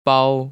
[bāo] 빠오